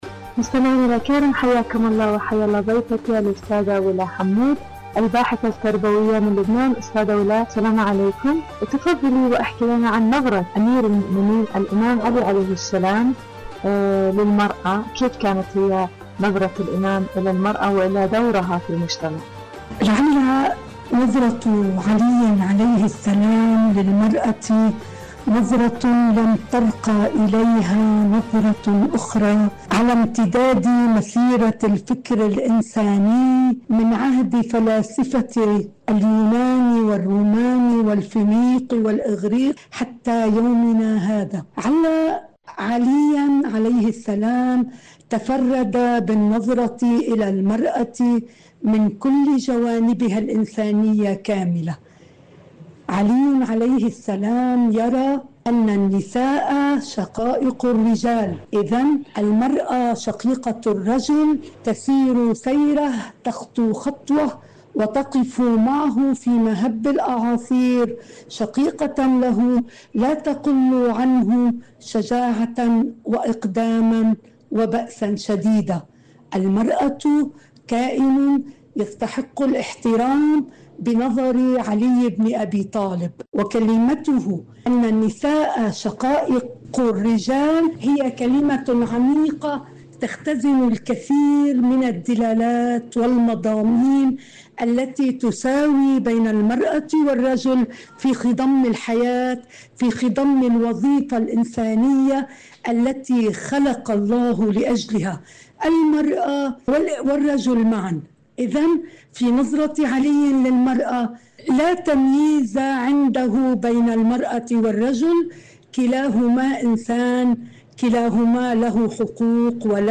مقابلة إذاعية